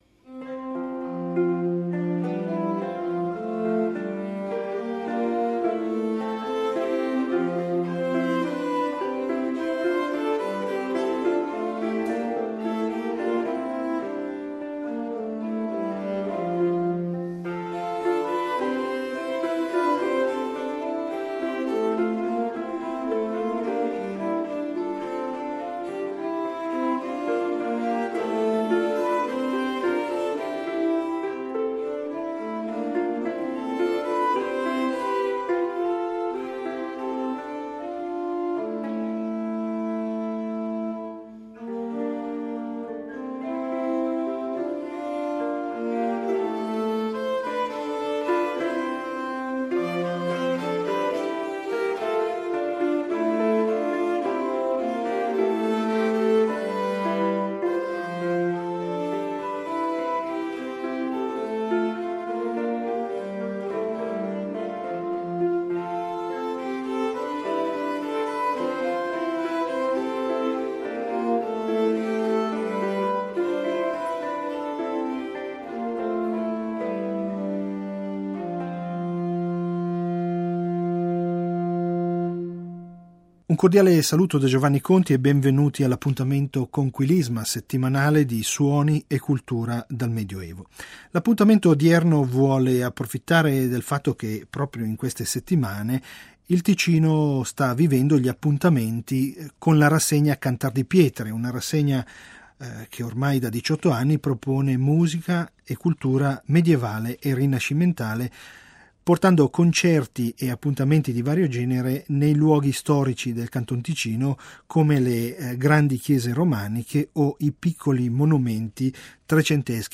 La Polifonia rinascimentale di Cipro